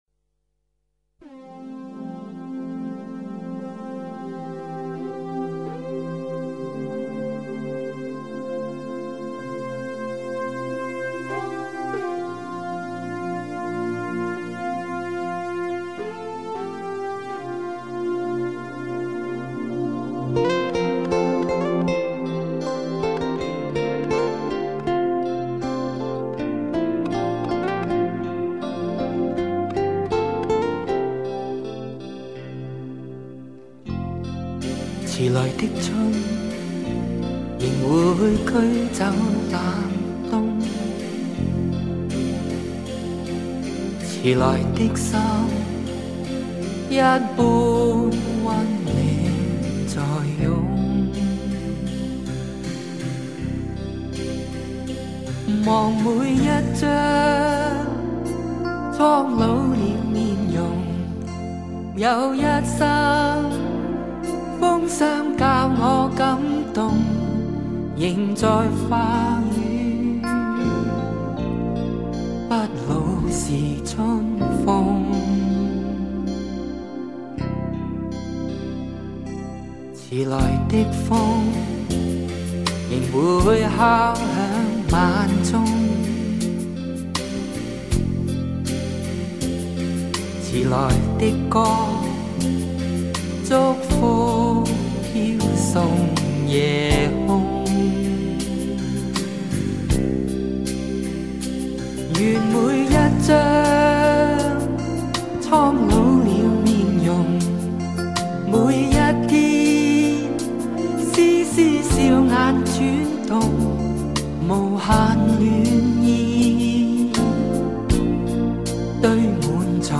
淡淡的暖暖的。